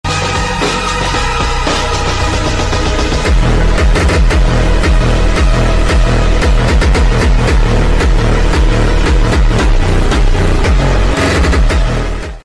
Nissan Skyline R34 Paul Walker Sound Effects Free Download